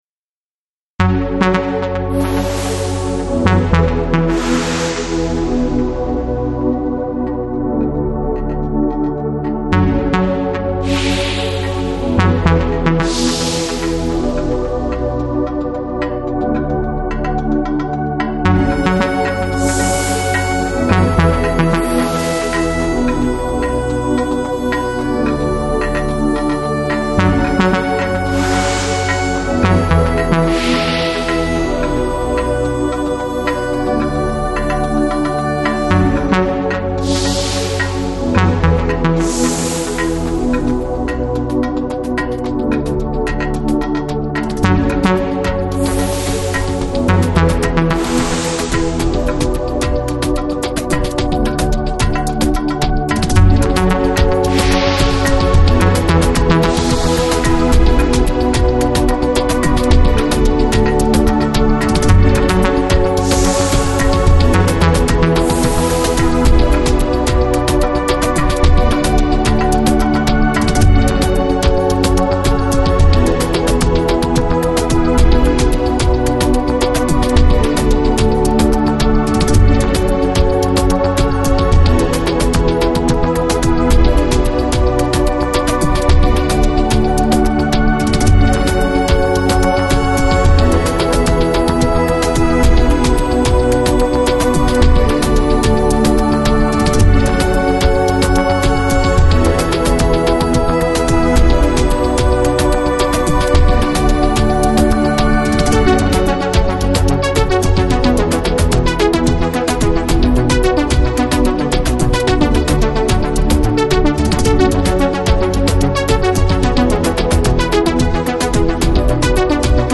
Electronic, Lounge, Chill Out, Downtempo, Balearic